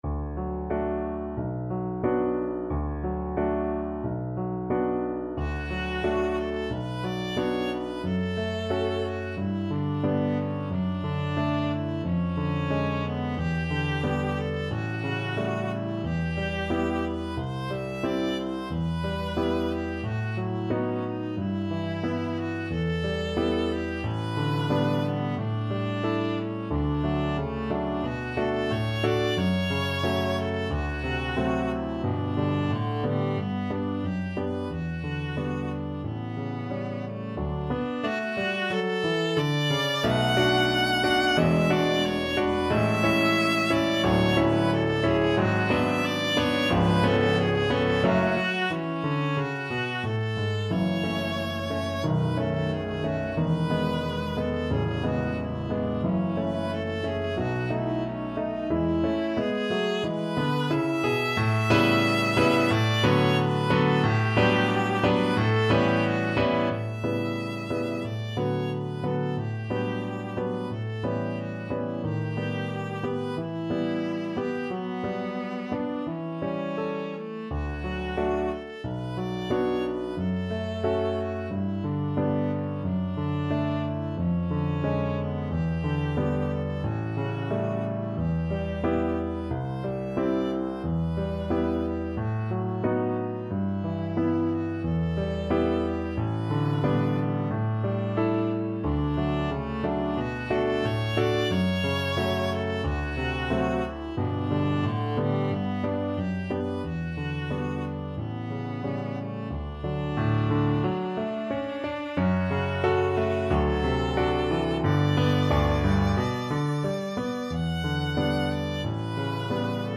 Viola version
2/4 (View more 2/4 Music)
~ = 100 Allegretto con moto =90
Classical (View more Classical Viola Music)